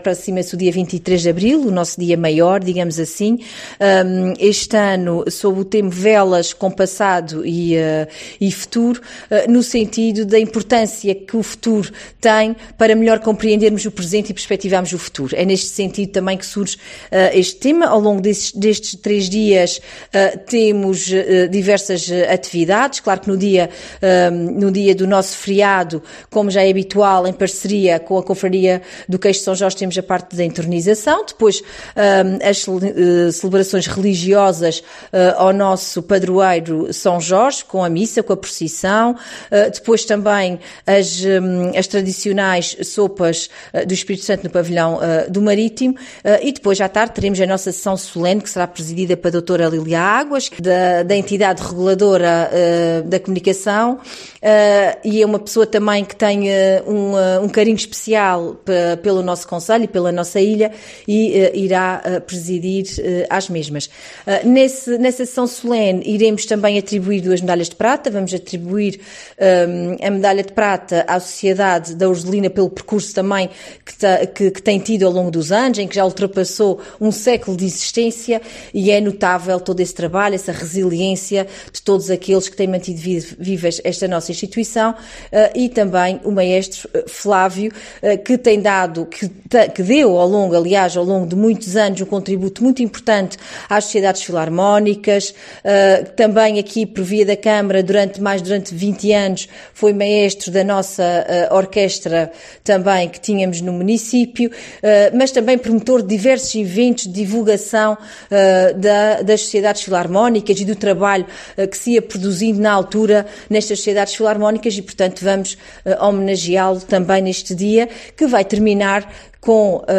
Declarações da presidente da Câmara das Velas, Catarina Cabeceiras, à Rádio Lumena sobre as Festas de São Jorge de 2026.